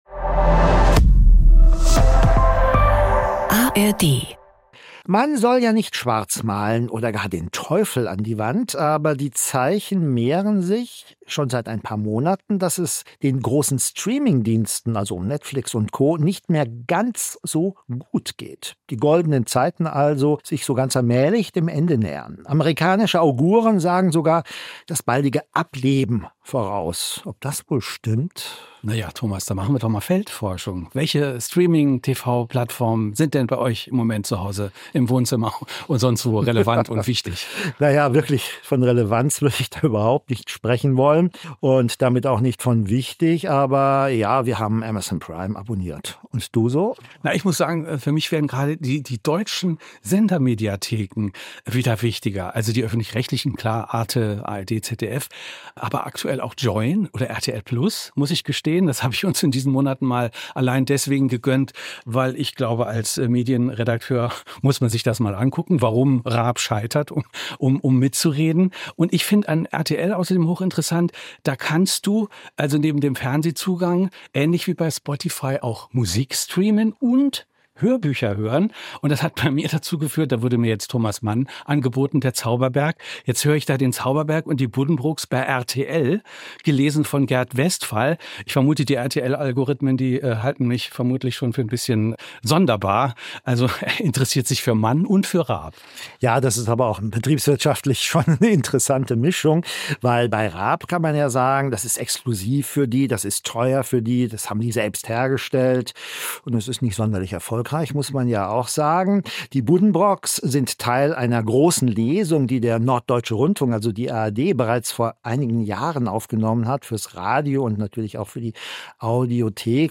Die Moderatorinnen und Moderatoren informieren, diskutieren und räsonieren jede Woche über neue Trends und kontroverse Themen aus der großen weiten Welt der Medien. Immer zu zweit, immer voller Meinungsfreude und immer auch mit fundierten und gründlich recherchierten Fakten. Gespräche mit Experten und Expertinnen, Umfragen unter Nutzerinnen und Nutzern und Statements aus der Medienbranche können Probleme benennen und Argumente untermauern.